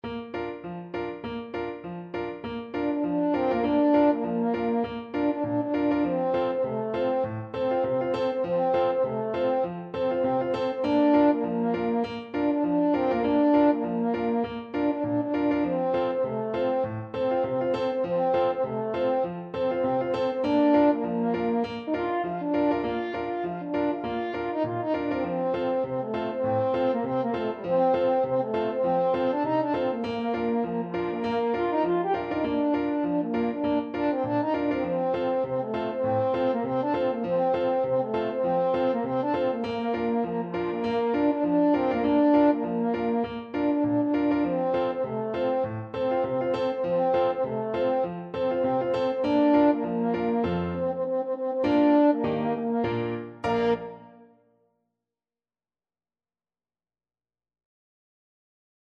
French Horn
2/2 (View more 2/2 Music)
Two in a bar with a light swing =c.100
Traditional (View more Traditional French Horn Music)